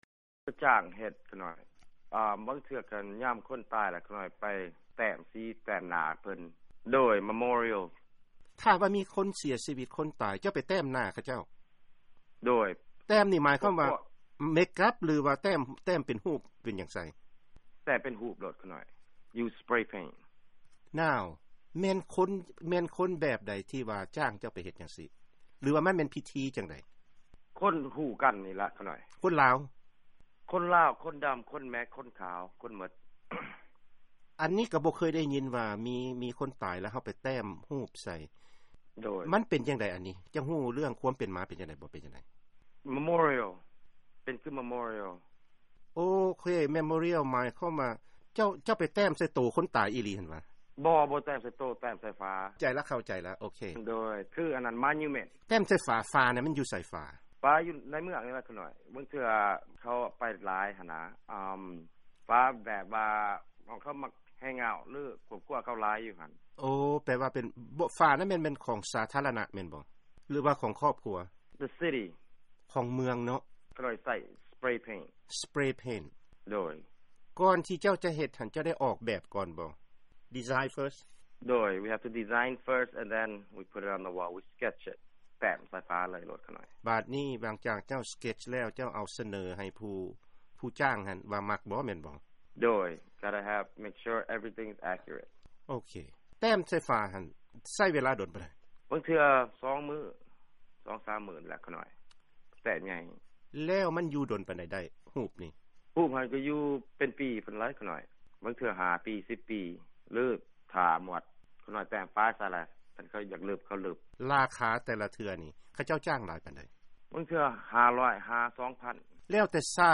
ວີໂອເອ ສຳພາດ
ດ້ວຍສຳນຽງປາກເຊ